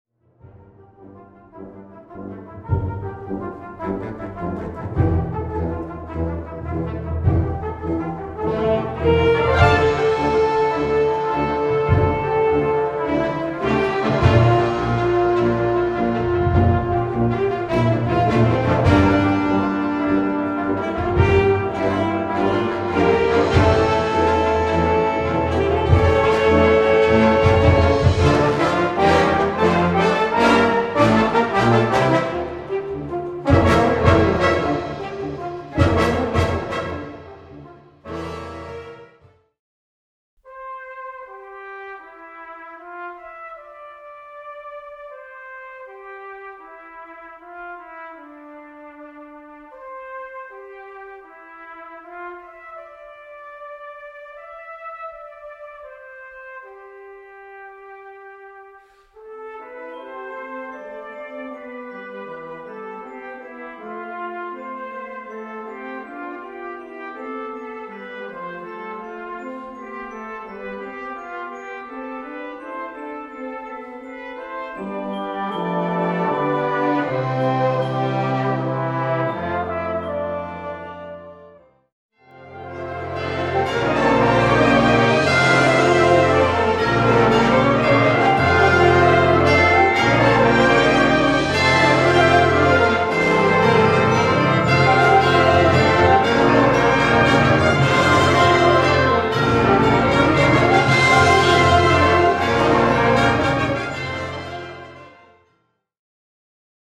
Opening Pieces / Indicatifs